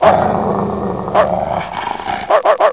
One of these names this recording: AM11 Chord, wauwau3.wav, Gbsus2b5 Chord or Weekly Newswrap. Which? wauwau3.wav